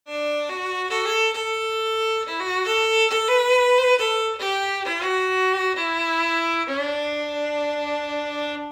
Bathrooms have the best acoustics!